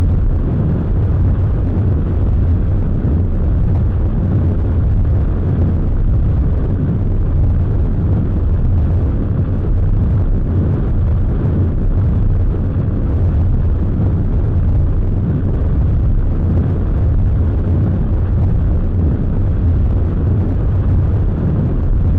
thrust5.mp3